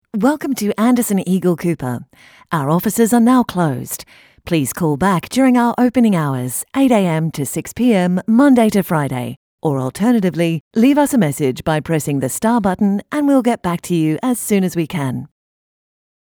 Boost caller engagement with professional on hold voice overs from experienced voice actors.
My voice is professional, upbeat, enthusiastic, sensual, fresh, warm, narrator, instructive, clear, corporate, friendly, strong, likable, happy, informative, conversational, animated, natural, articulate, versa...